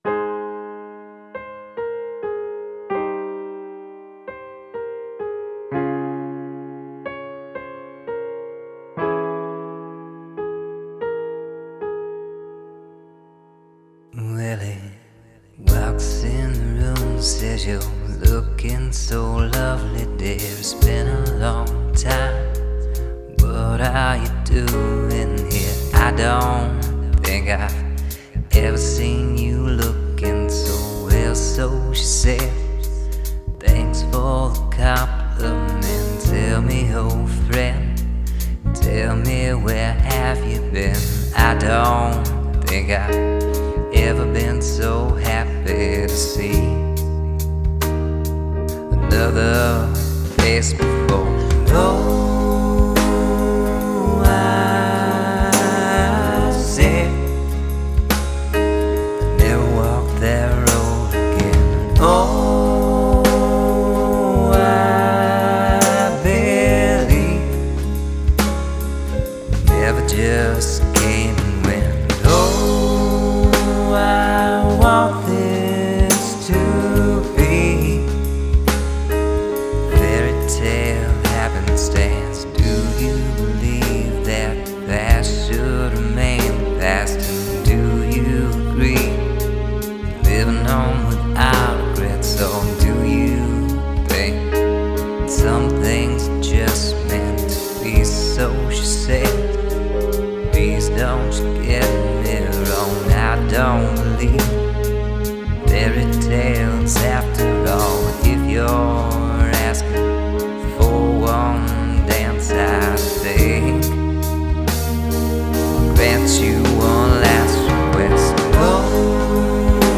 Genre: singersongwriter.